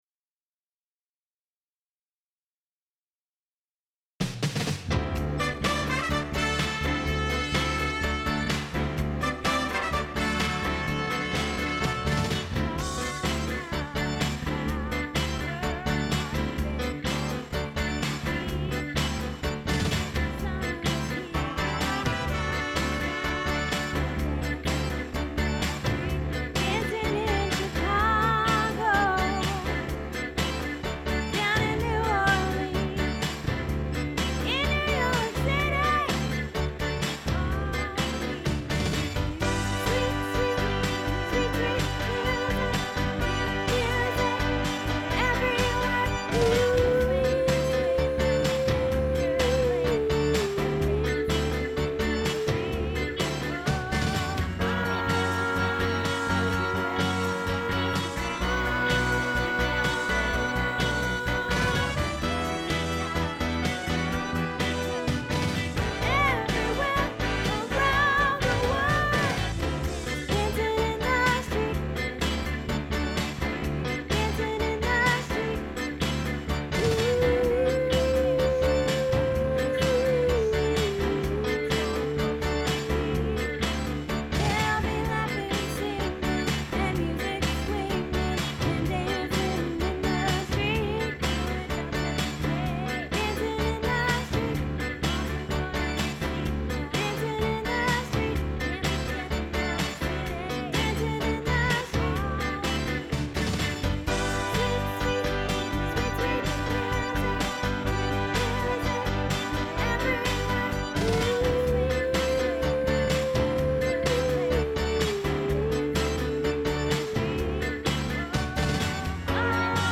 Dancing in the Streets Soprano